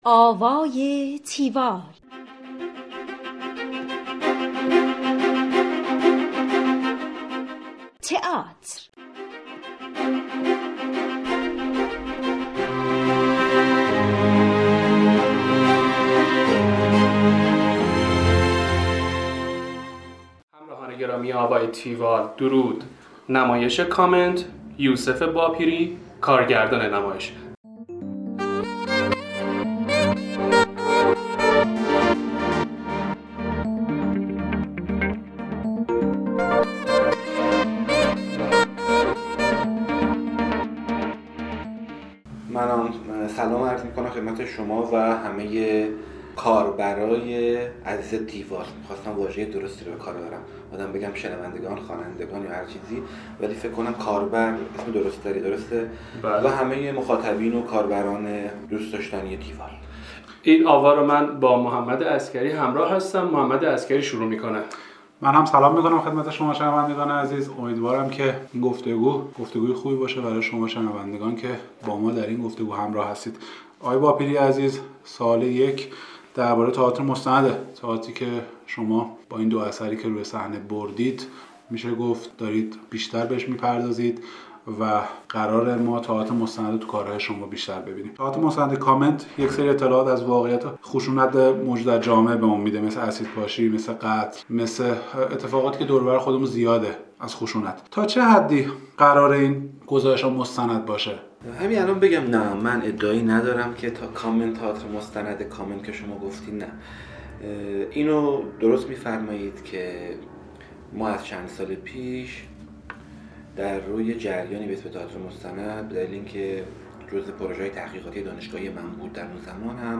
کارگردان گفتگو کننده